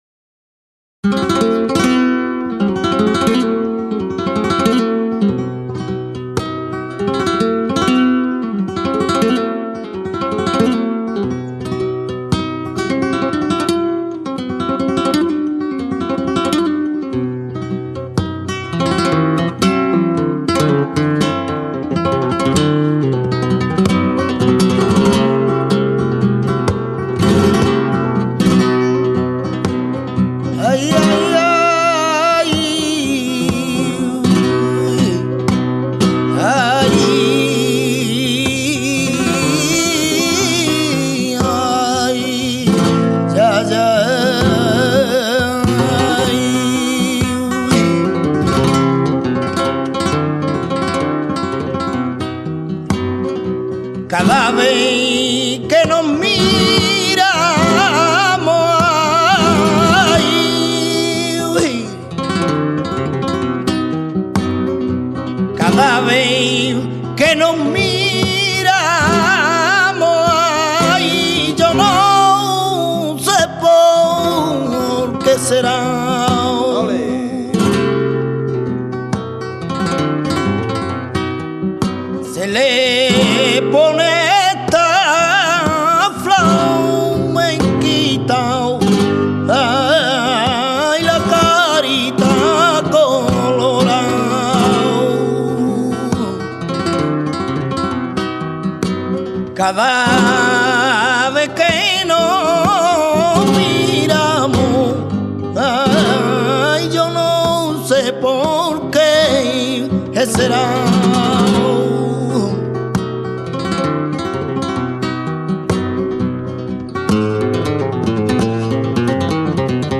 Soleares